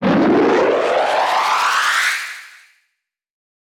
jet1.wav